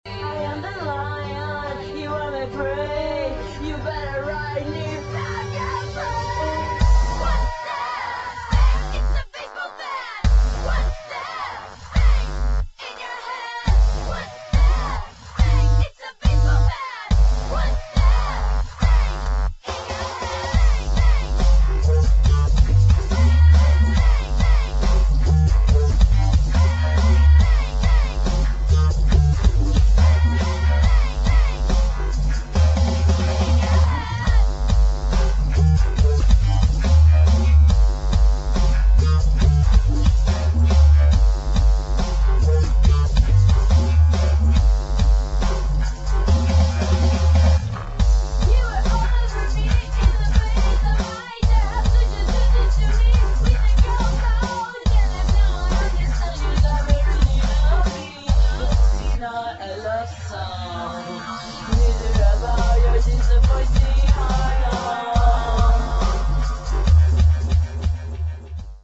[ DUBSTEP | HOUSE ]